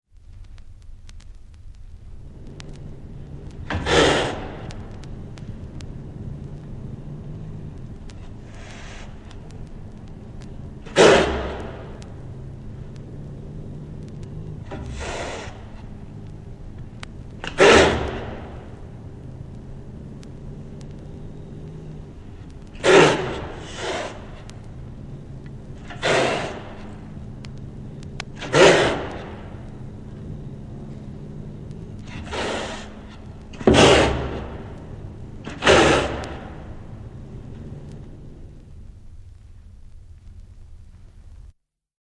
Звук записан в лондонском зоопарке каракал яростно кидается через клетку на зрителей но не достает